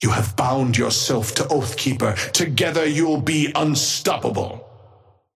Patron_male_ally_ghost_oathkeeper_5g_start_02.mp3